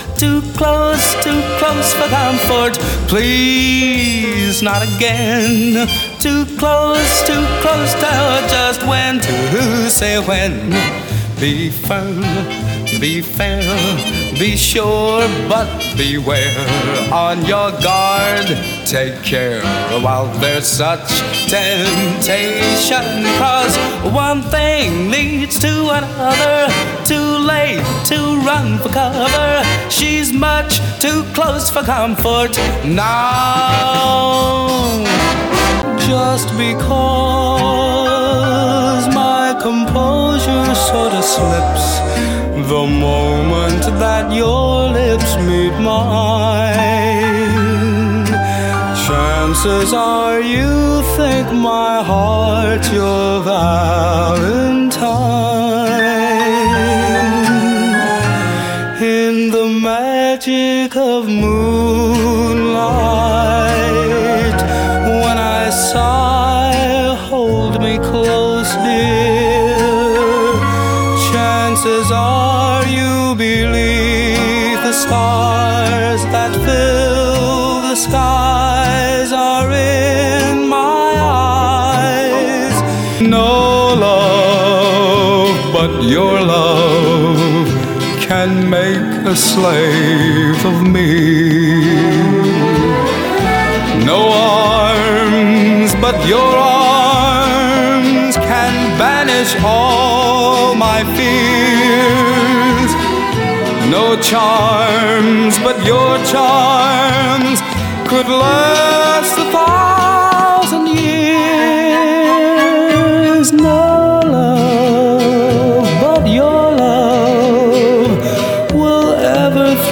sing a cappella with just a choral backing